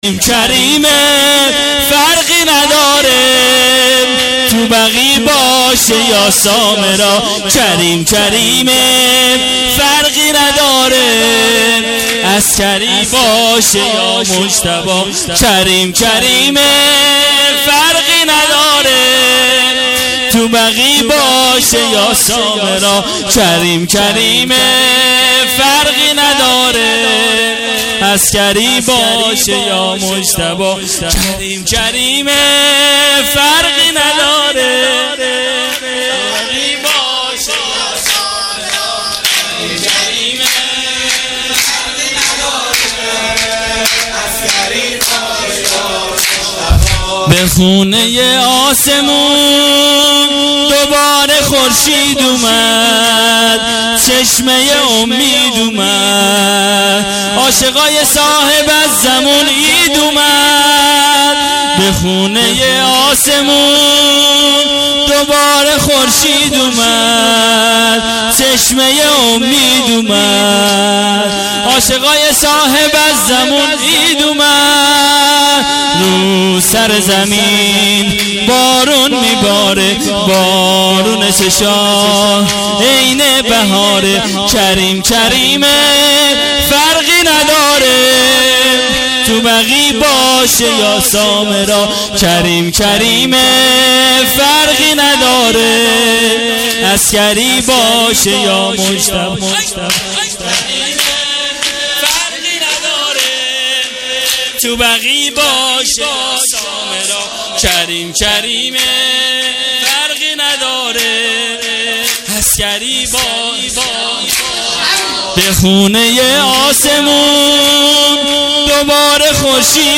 هیئت عاشورا-قم